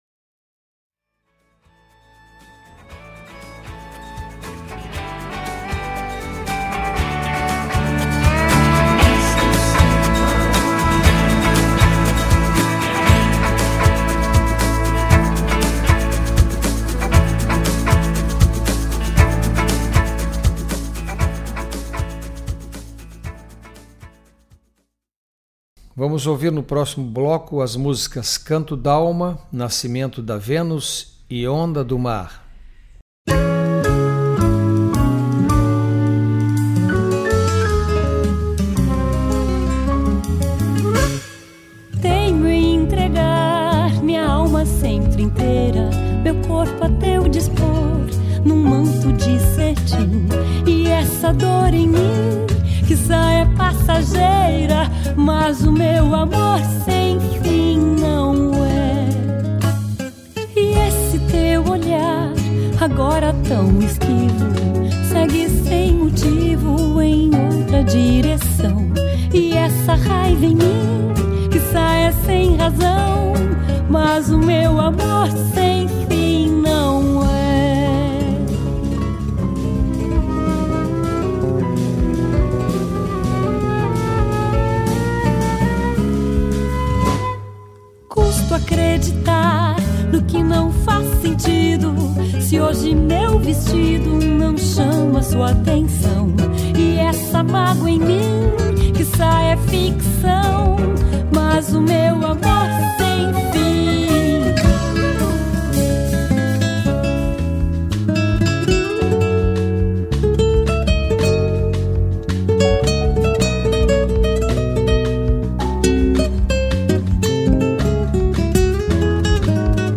diversos gêneros musicais brasileiros
um joropo - gênero típico da música crioula
bateria
acordeom
piano acústico
violino e rabeca